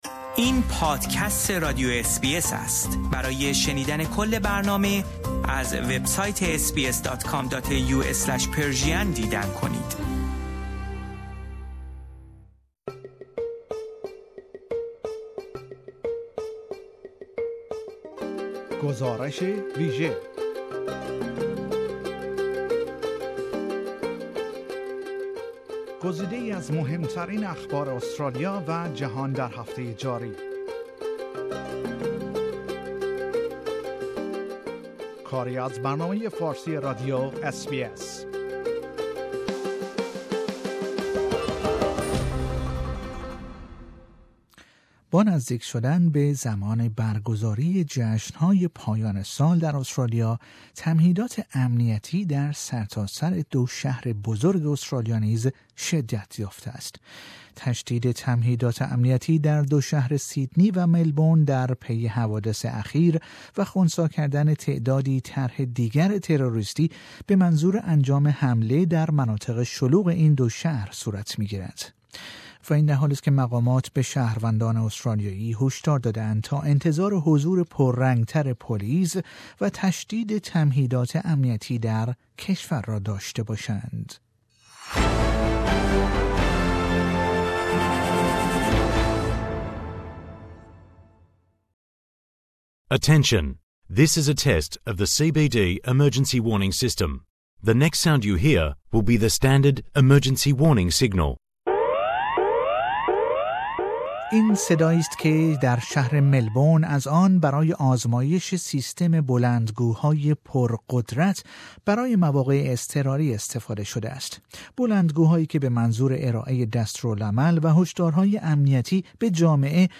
این صدایی است که در شهر ملبورن از آن برای آزمایش سیستم بلندگوهای پر قدرت برای مواقع اضطراری استفاده شده است.